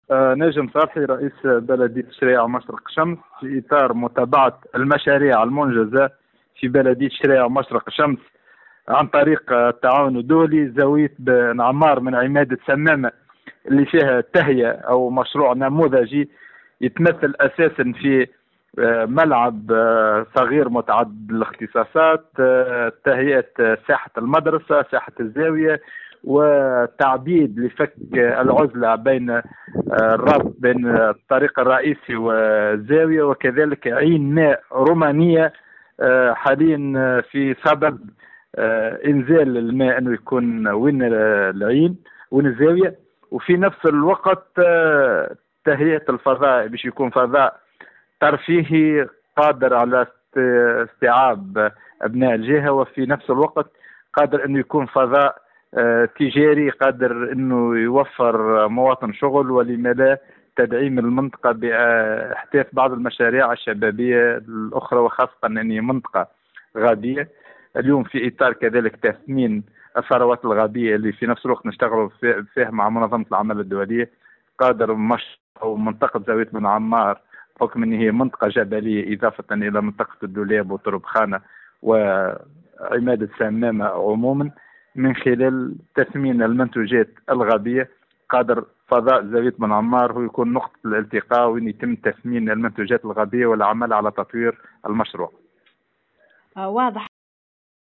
Kasserine : Les travaux de réaménagement du village Zaouiet Ben Ammar avancent à grands pas (Déclaration)
Dans une déclaration faite à notre correspondant, le maire de la municipalité de Charayaa-Machrek Chams, Najem Salhi a fait savoir que le projet de réaménagement de cette village a pour objectif d’améliorer la qualité de vie des habitants du village tout luttant contre l’exode rural et en rendant la localité une zone attractive.